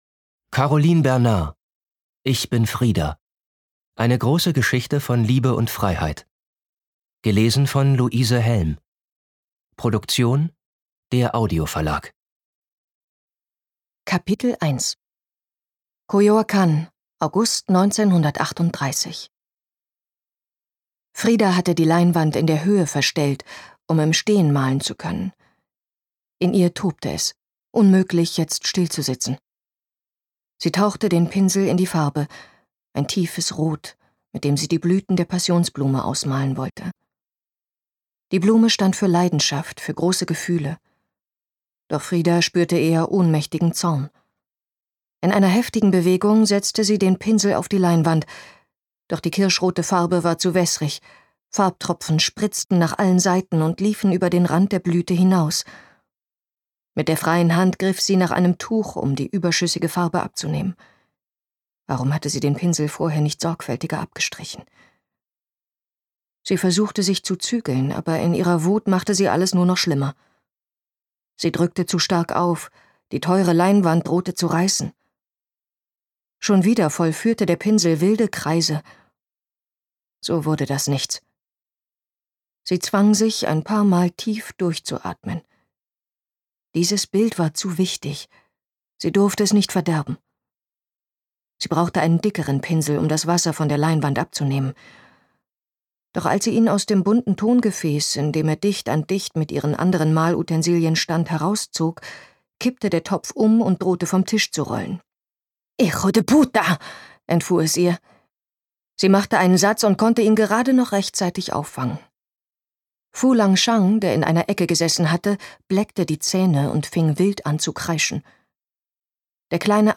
Ungekürzte Lesung mit Luise Helm (1 mp3-CD)
Luise Helm (Sprecher)